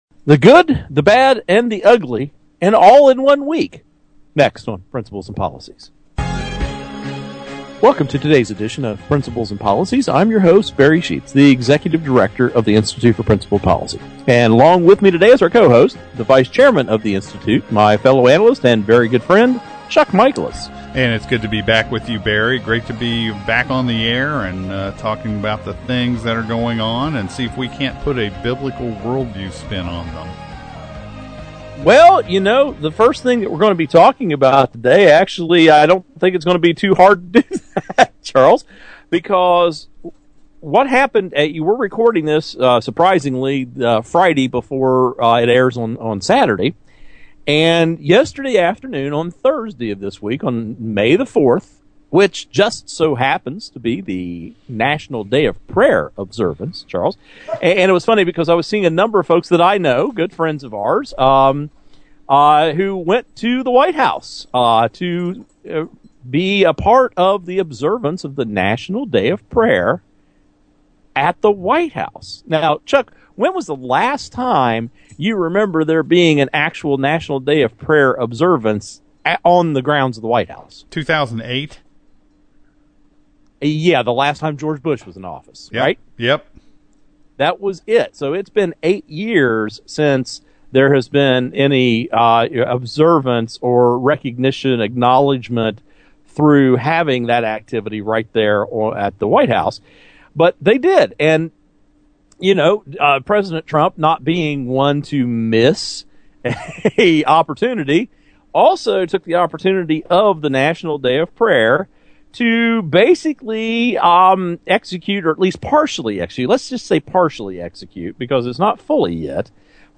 Our Principles and Policies radio show for Saturday May 6, 2017.